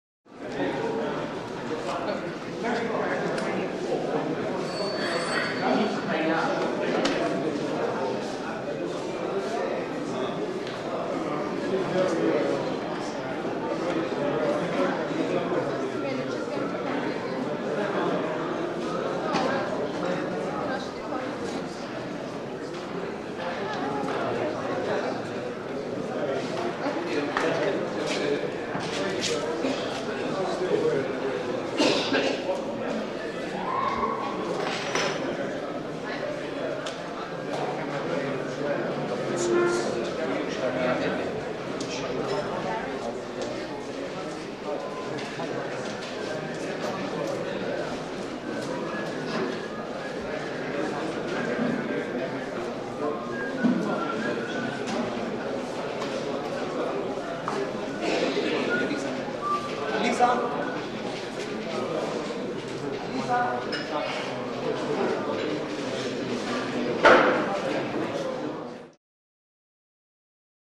Hotel; Israeli; Aram Hotel Jerusalem. Busy Echoed Mid Shot Activity And Chatter.